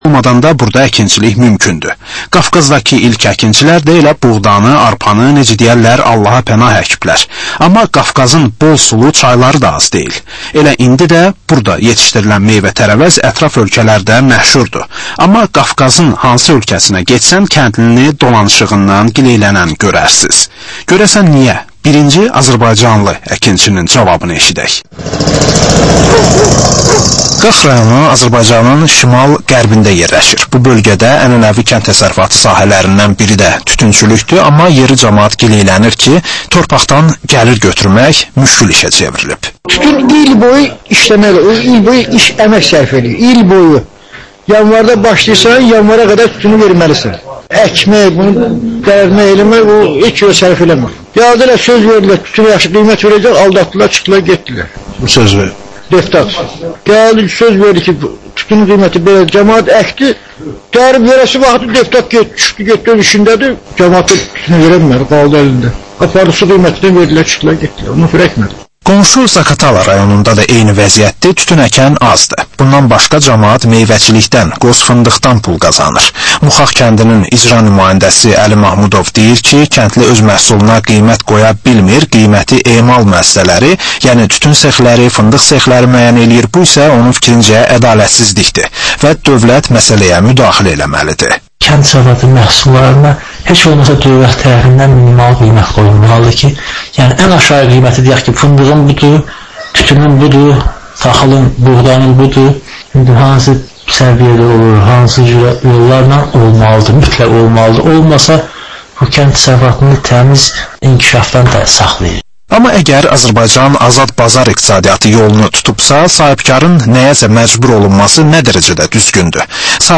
Azərbaycan, Gürcüstan və Ermənistandan reportajlar.